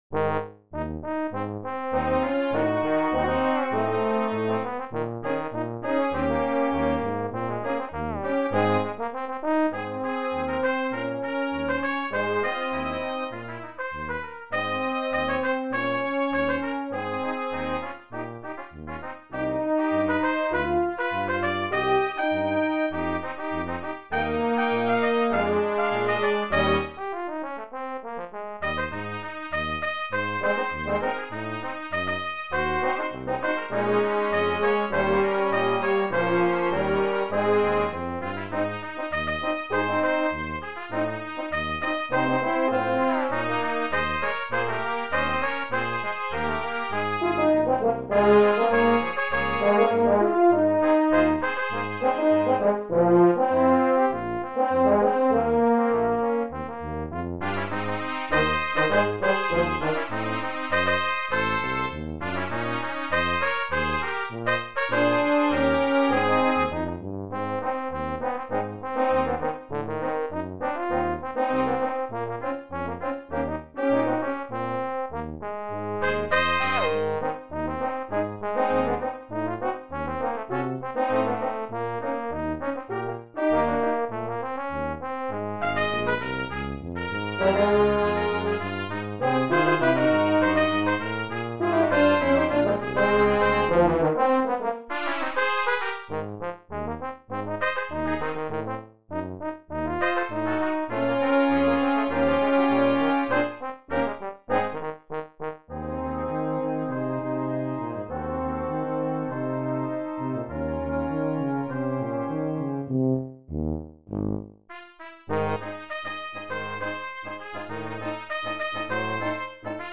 Brass Quintet
French horn and tuba also get a piece of the melody.